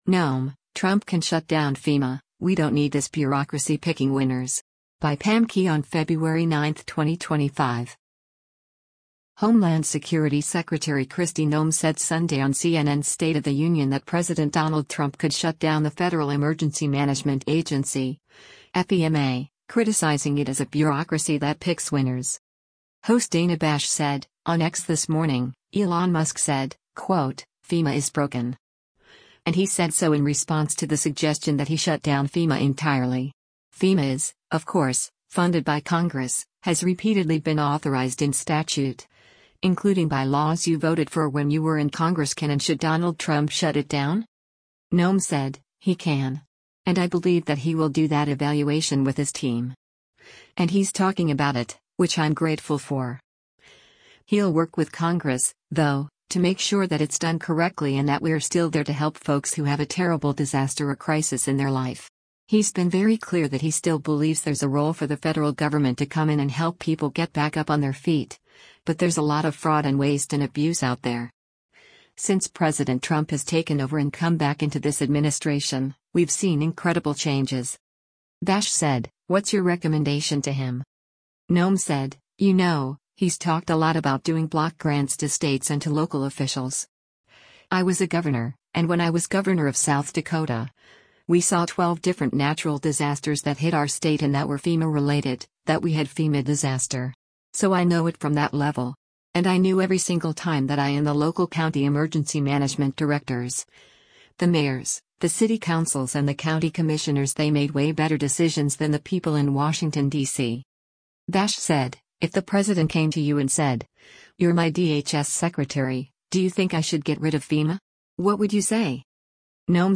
Homeland Security Secretary Kristi Noem said Sunday on CNN’s “State of the Union” that President Donald Trump could shut down the Federal Emergency Management Agency (FEMA), criticizing it as a bureaucracy that picks winners.